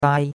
dai1.mp3